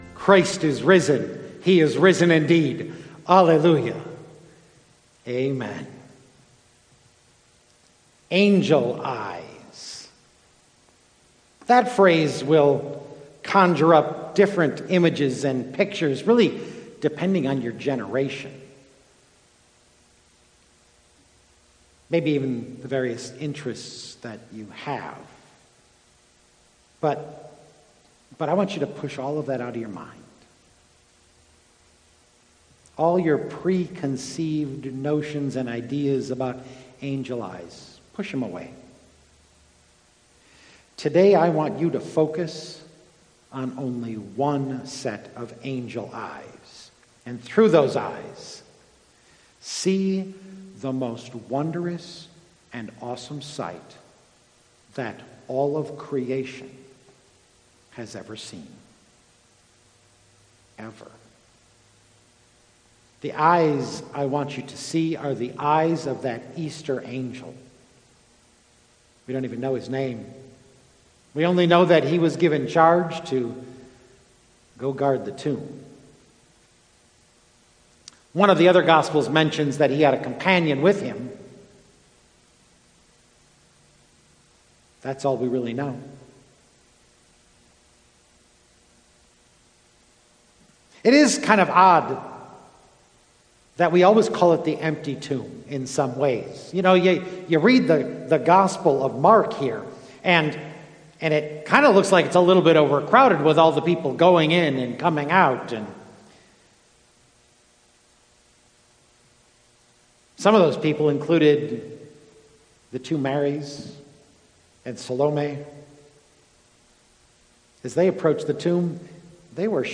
04-12-Angel-Eyes-Sermon-Audio.mp3